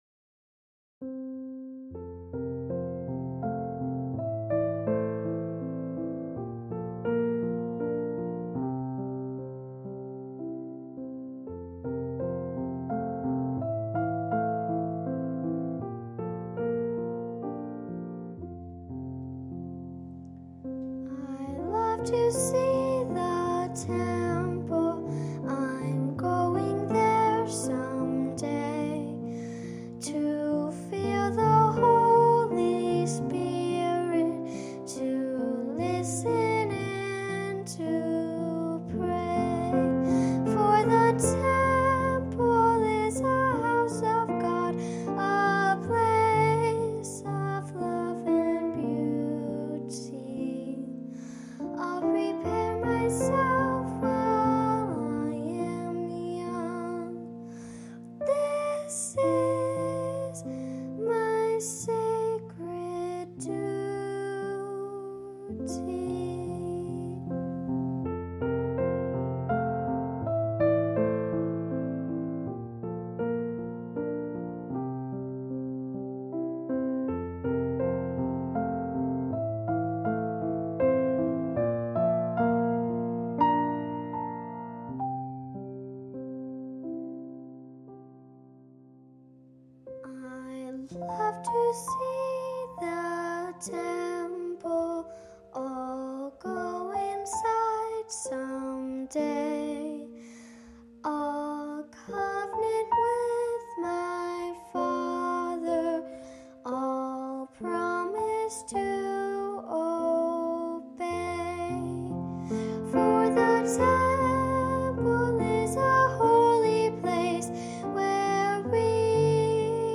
A choral arrangment
Voicing/Instrumentation: Primary Children/Primary Solo